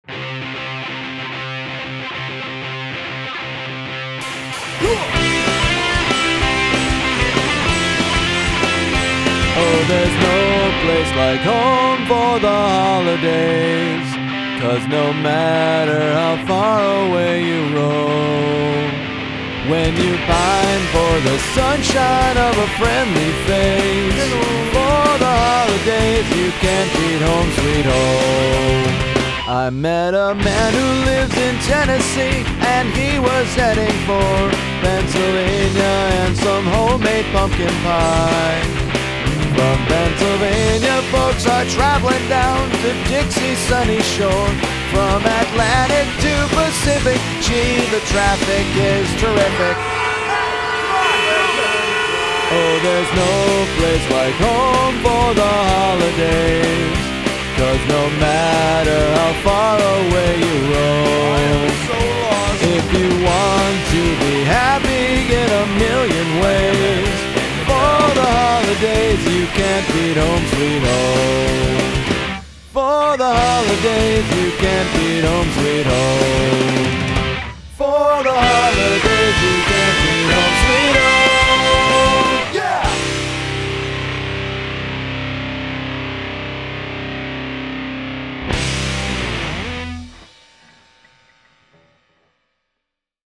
Mixed at Thea Partment, Columbus, OH.